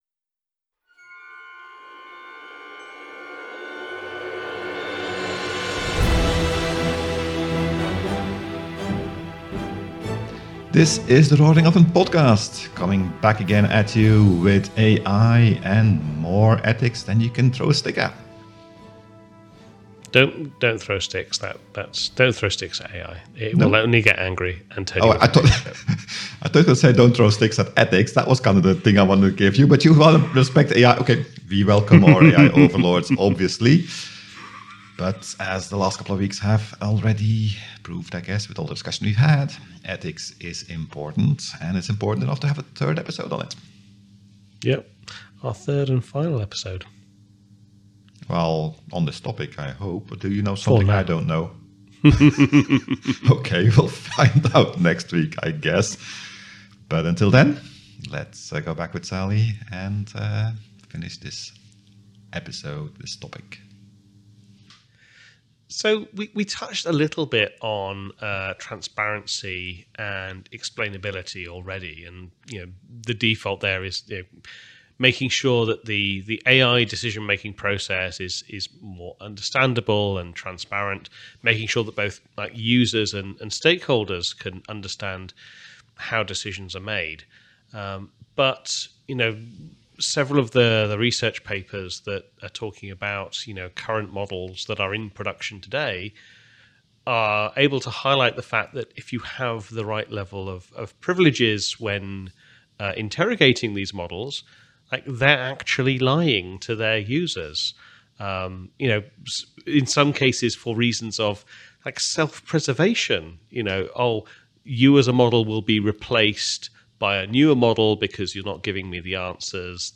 a lively discussion on the topic.